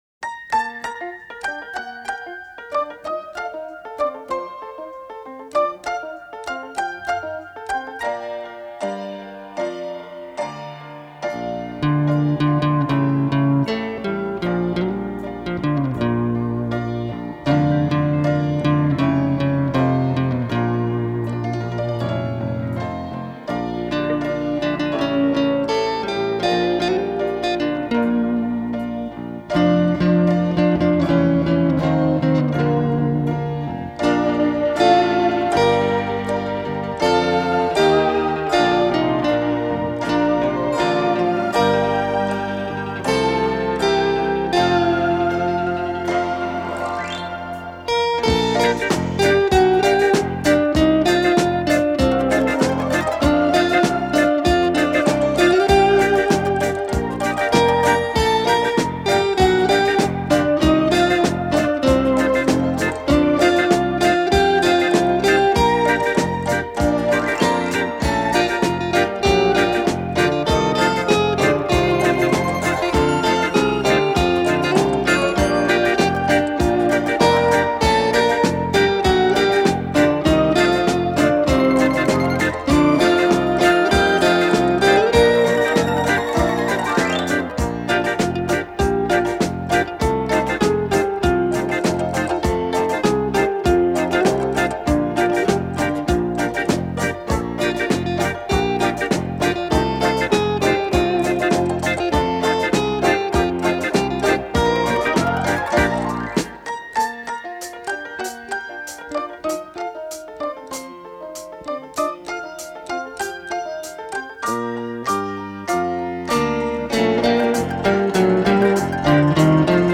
音乐风格 : Instrumental / All Genre